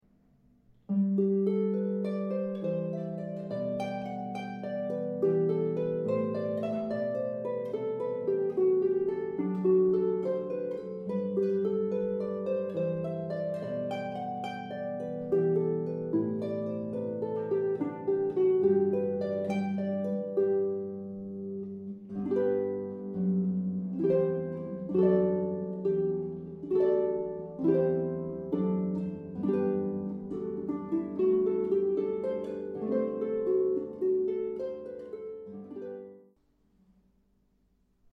Live harp, piano, or organ music for weddings or special events in the Bay Area